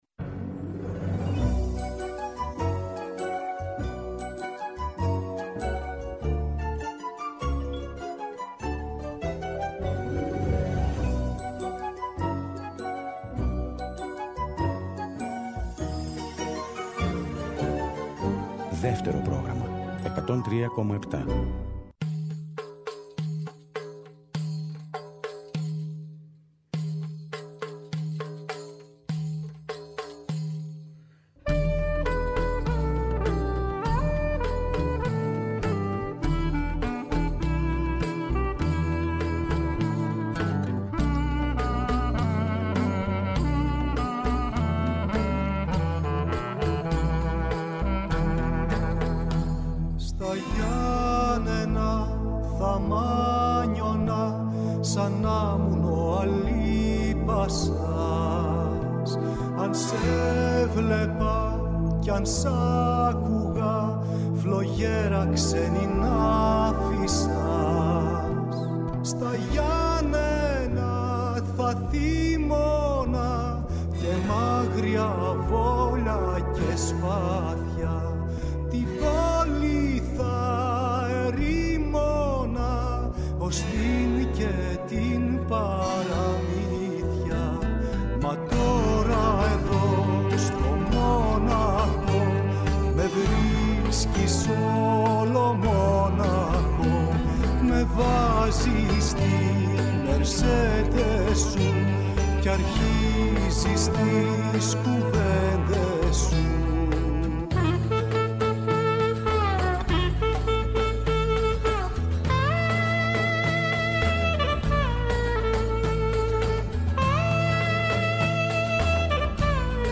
Βόλτες στις μελωδίες, τους ήχους και τους στίχους από όλες τις εποχές του ελληνικού τραγουδιού, διανθισμένες με παρουσιάσεις νέων δίσκων, κινηματογραφικών εντυπώσεων, αλλά και ζεστές κουβέντες με καλλιτέχνες από τη θεατρική επικαιρότητα.